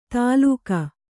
♪ tālūka